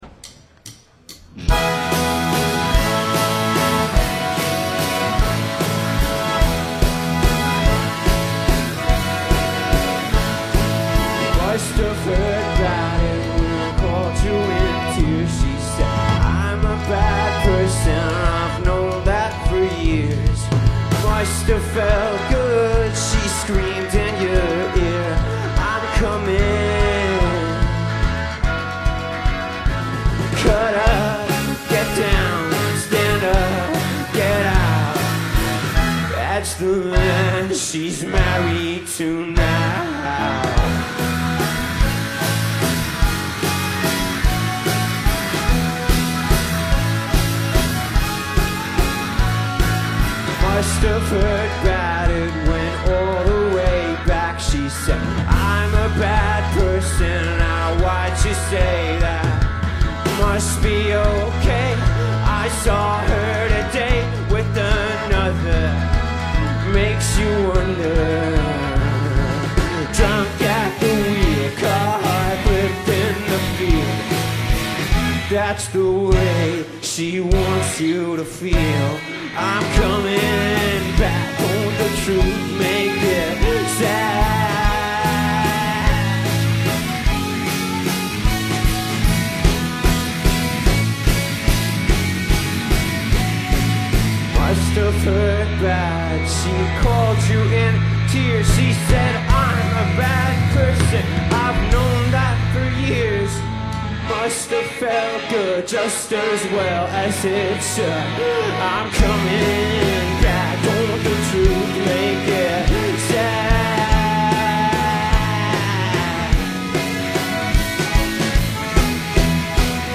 a six-piece band from Cork
bring a raw, scuzzy energy
sweet, pop-centric vocal melodies
accordion
Their music often evokes a layered, otherworldly quality